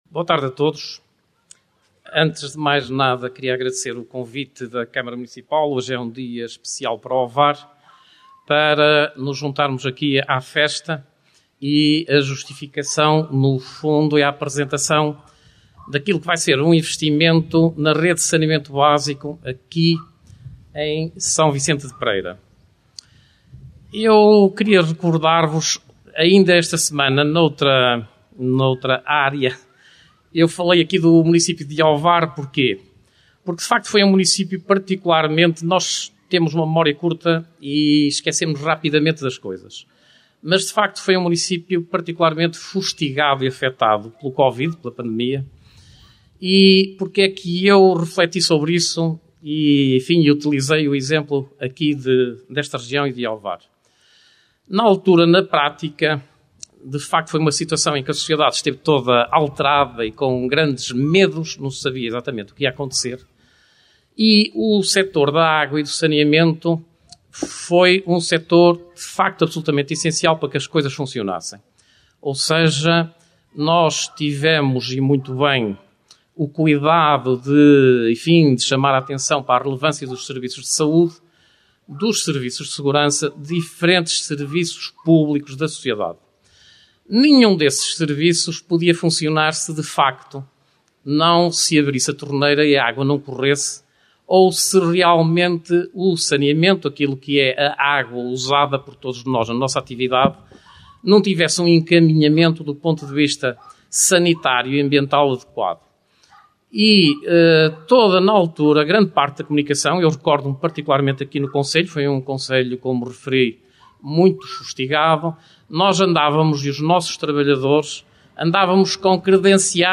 O último ato público decorreu em S. Vicente de Pereira Jusã, com a Inauguração do Parque de Lazer e Bem-Estar e com a Apresentação do Projeto “Águas Residuais do Sistema de S. Vicente de Pereira (PAR – 100) – 2ª Fase A – Ovar”, uma obra da Águas da Região de Aveiro (AdRA).